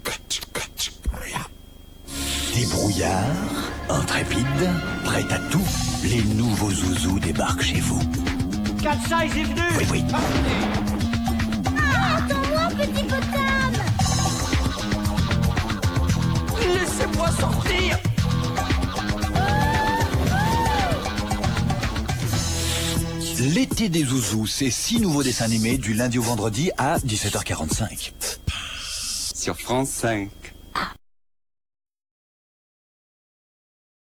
Promo | L'ETE DES ZOUZOUS